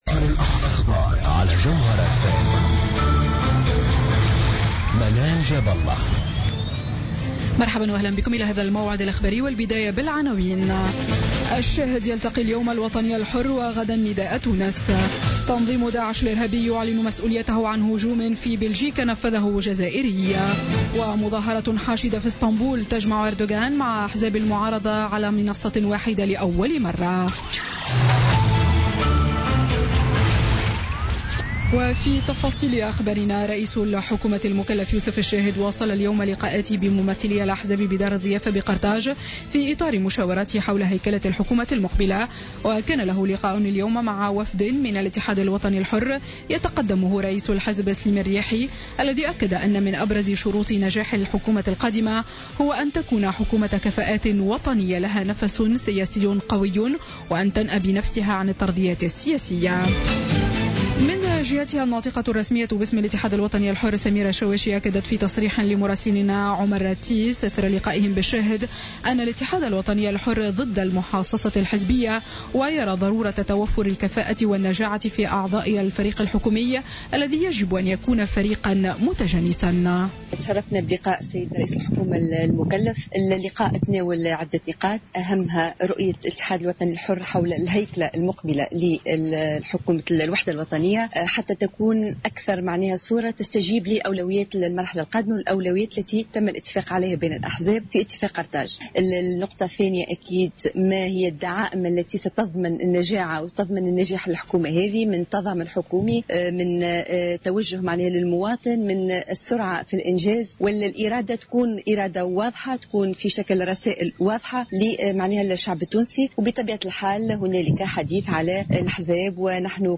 نشرة أخبار السابعة مساء ليوم الأحد 7 أوت 2016